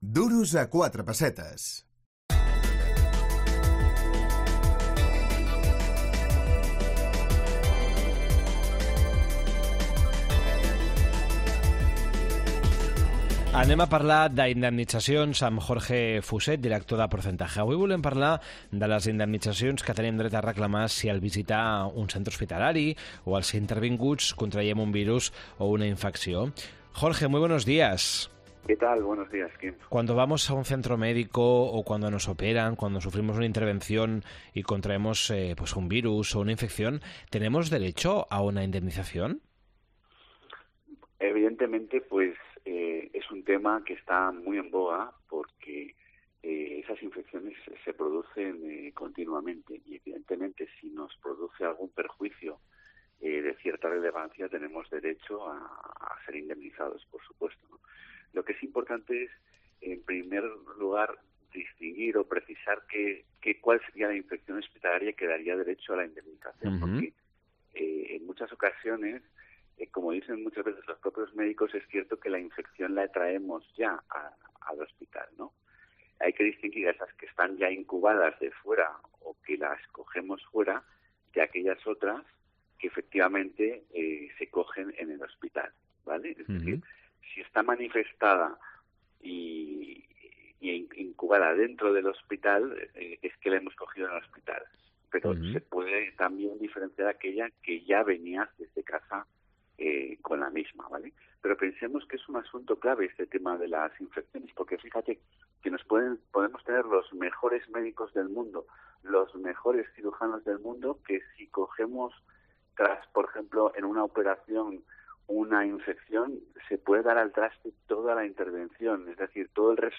Indemnitzacions hospitalàries. Entrevista